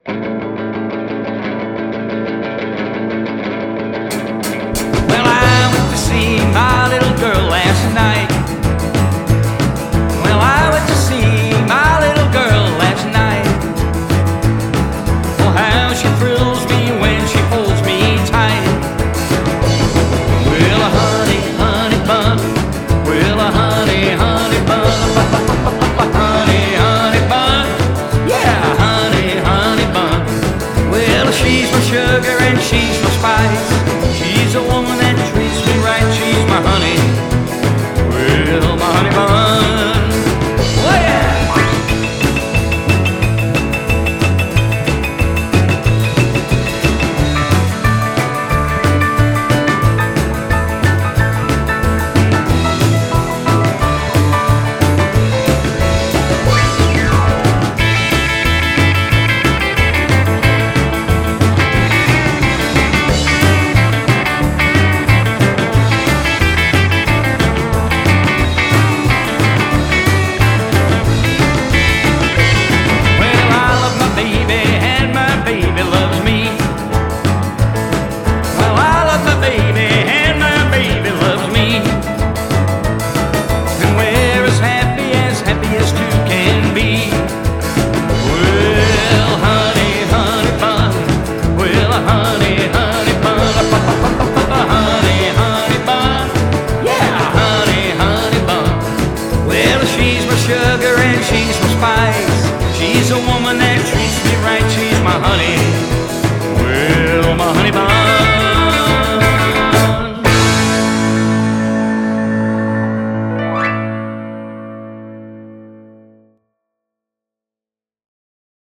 rock ’n’ roll